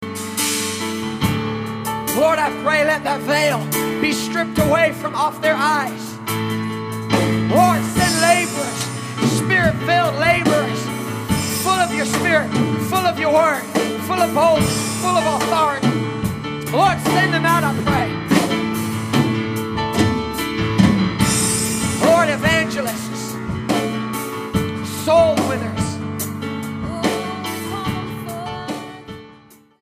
STYLE: MOR / Soft Pop
piano-backed powerful and uplifting prayer ministry